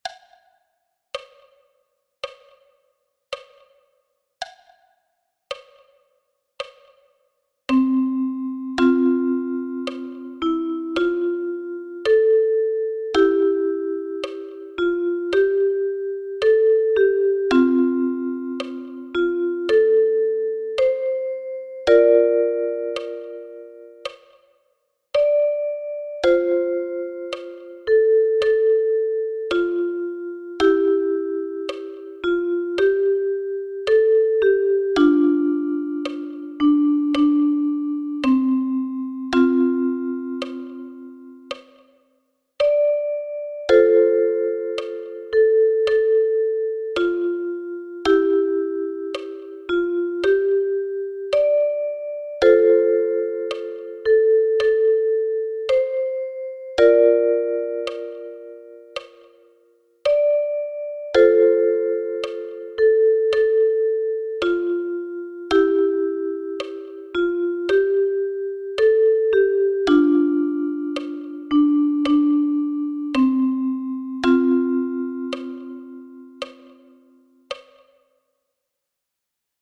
Jeden ersten Freitag im Monat findest du hier einen Song für die Uke – präsentiert mit der bekannten Tabulatur und Sound(s) zum Anhören und Mitspielen.
Diese neue Blogreihe startet mit dem schottischen Volkslied „Auld Lang Syne“, publiziert erstmals im Jahre 1711 von James Watson.
Traditional